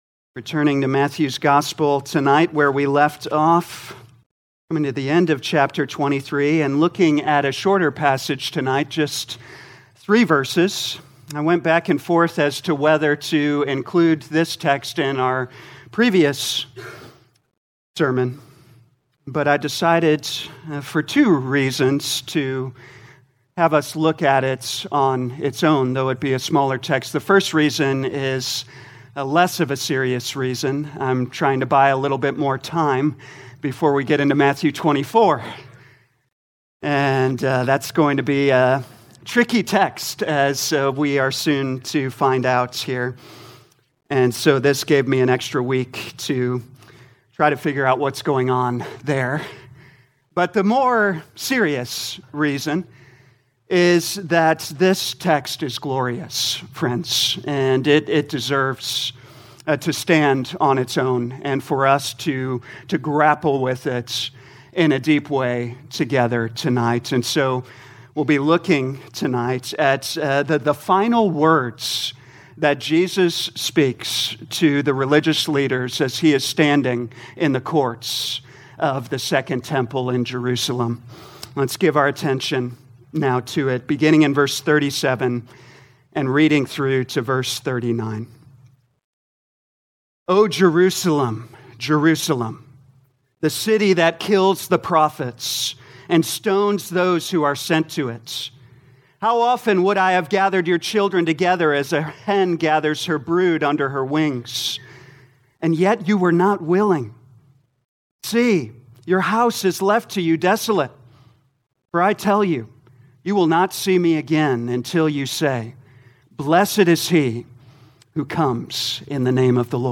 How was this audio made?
2024 Matthew Evening Service Download